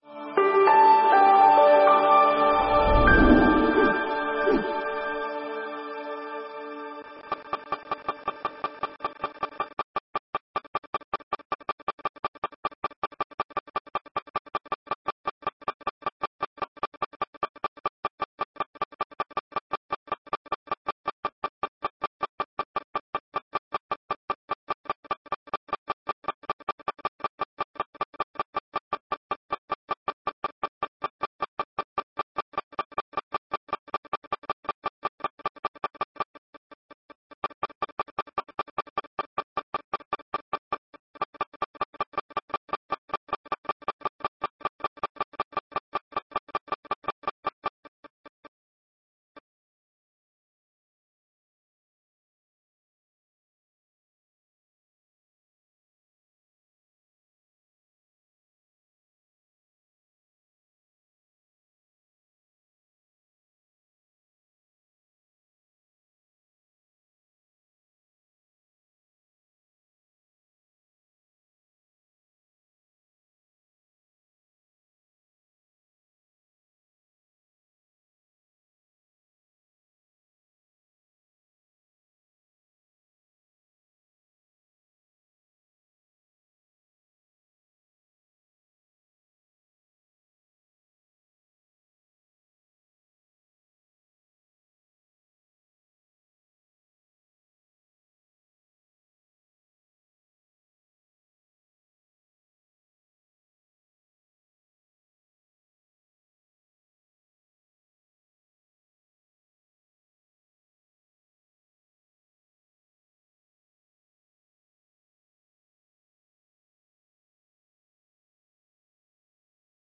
איך להדליק את האש בנפש? חמש עצות- שיעור לחנוכה